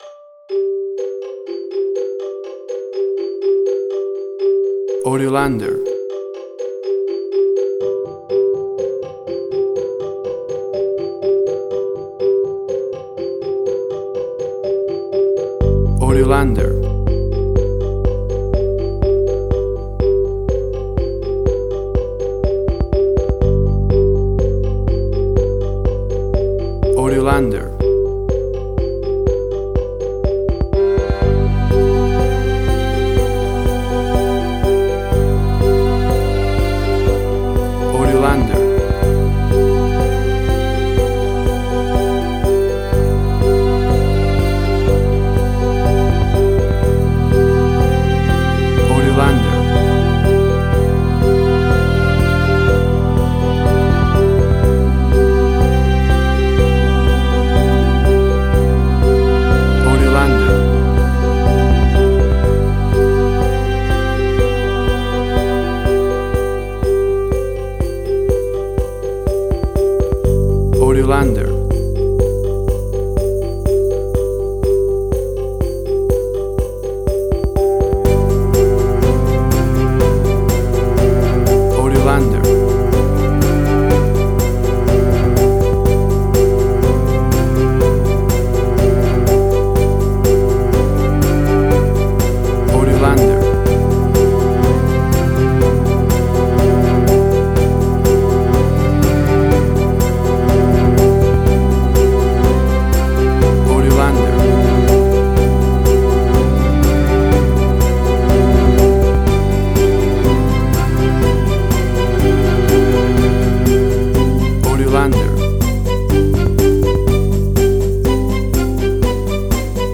Suspense, Drama, Quirky, Emotional.
Tempo (BPM): 123